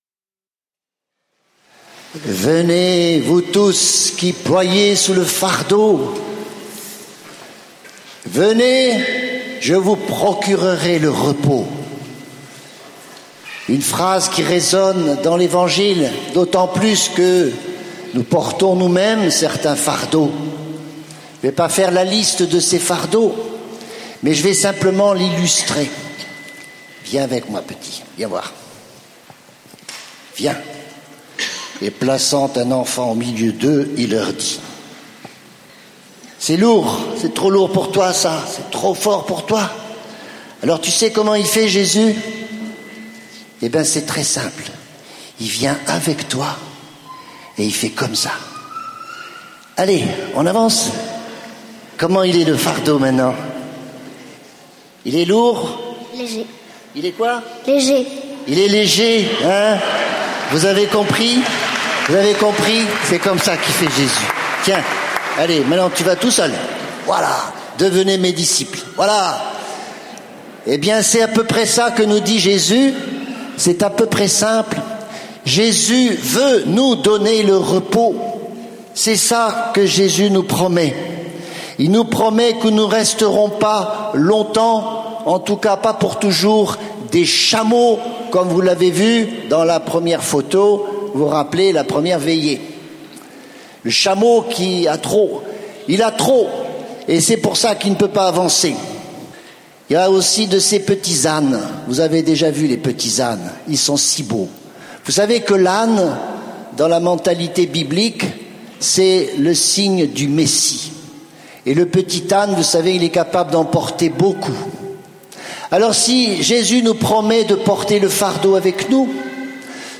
Enregistré en 2016 (Session Béatitudes Lourdes)
(09:40mn Mgr Marc Aillet) Lourdes 2016 - Homélie mercredi (1.50 EUR)